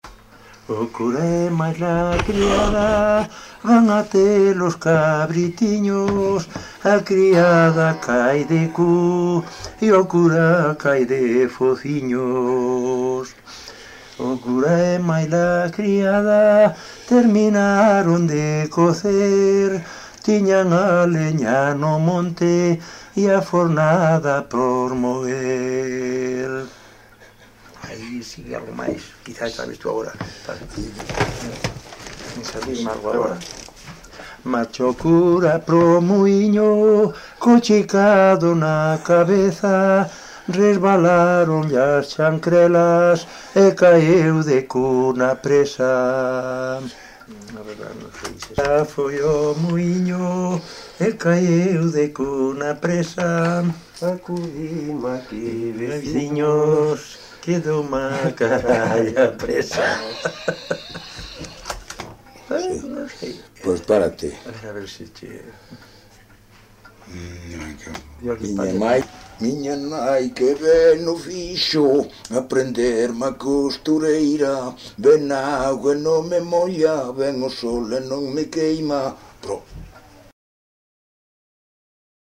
Tipo de rexistro: Musical
Áreas de coñecemento: LITERATURA E DITOS POPULARES > Coplas
Lugar de compilación: Fonsagrada, A - Fonfría (Santa María Madanela) - Fonfría
Soporte orixinal: Casete
Instrumentación: Voz
Instrumentos: Voz masculina